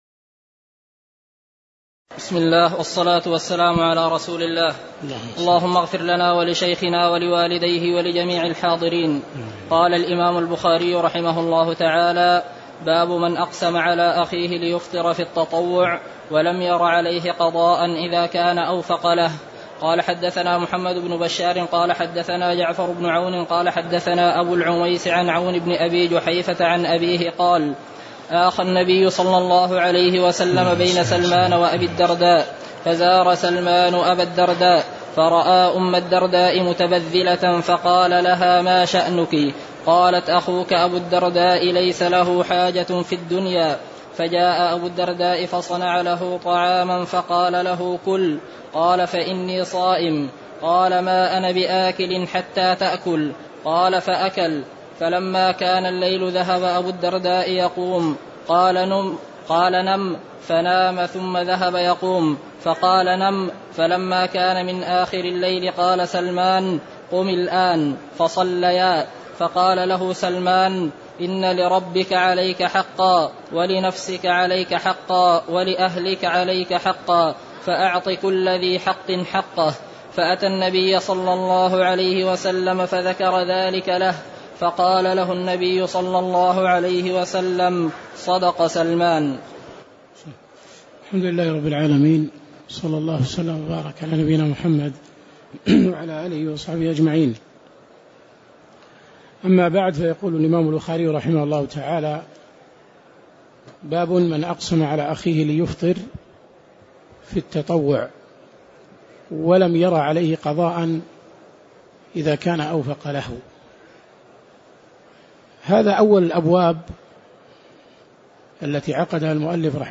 تاريخ النشر ١٣ رمضان ١٤٣٨ هـ المكان: المسجد النبوي الشيخ